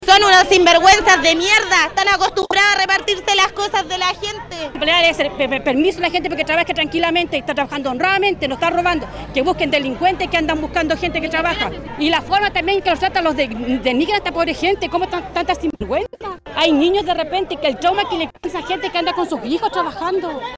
En medio de los incidentes, los peatones conversaron con La Radio y expresaron su descontento con la situación.
peatones3.mp3